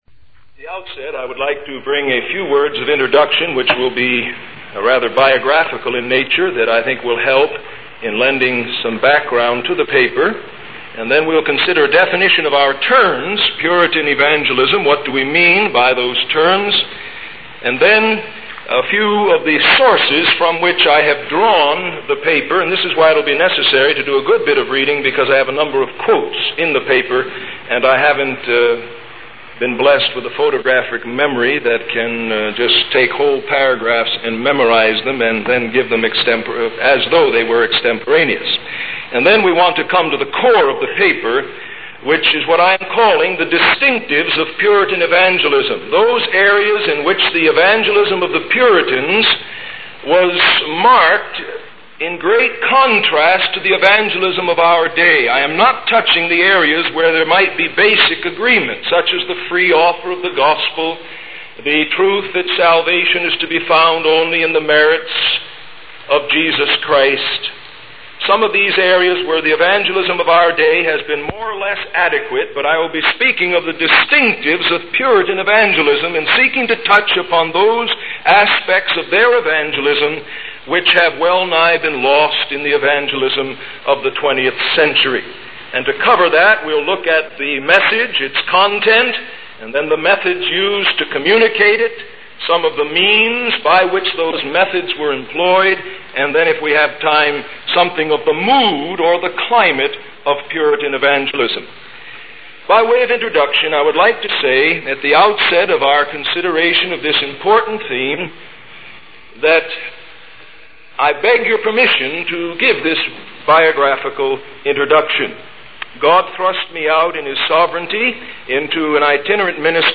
In this sermon, the speaker begins by sharing his personal journey into ministry and the importance of preaching the word of God.